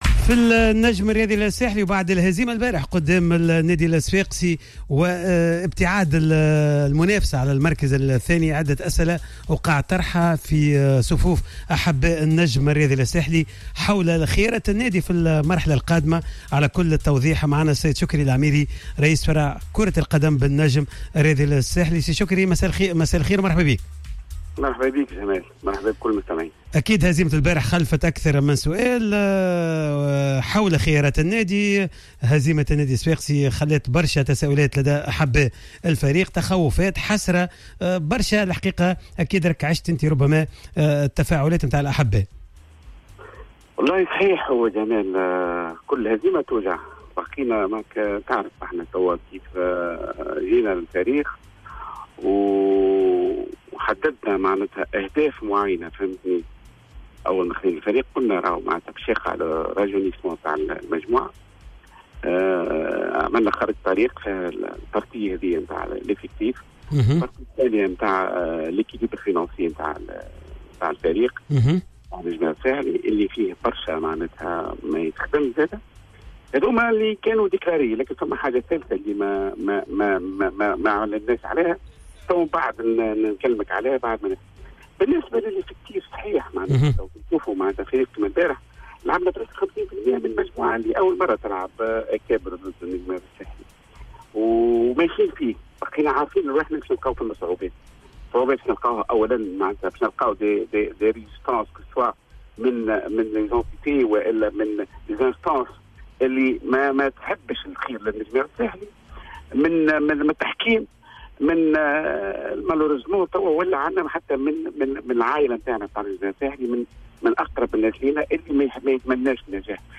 تصريح للجوهرة اف ام